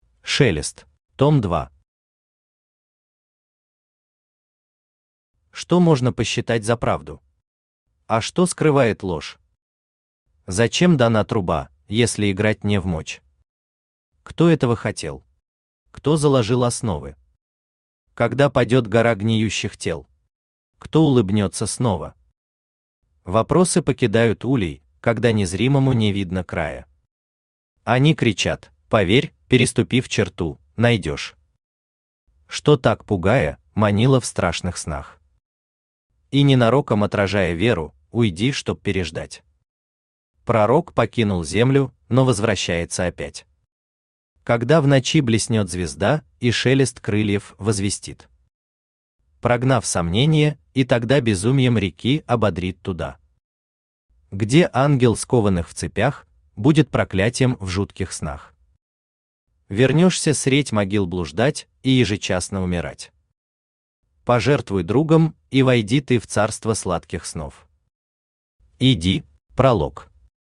Аудиокнига Шелест. Том 2 | Библиотека аудиокниг